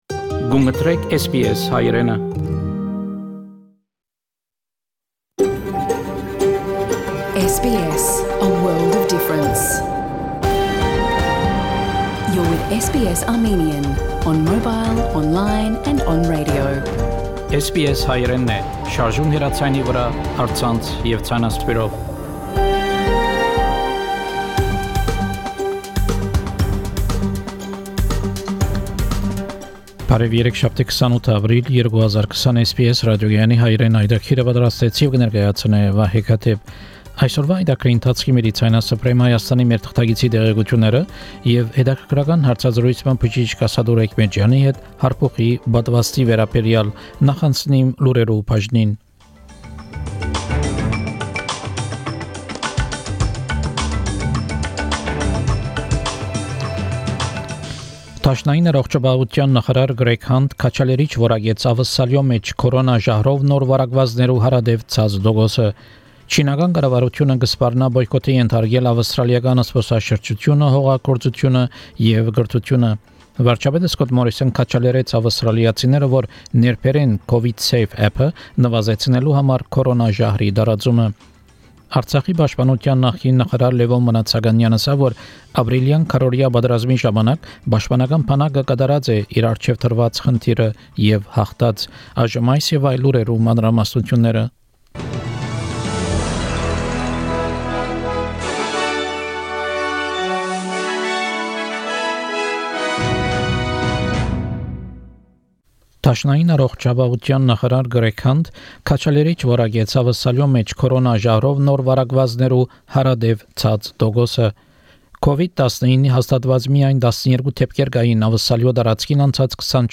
SBS Armenian news bulletin - 28 April 2020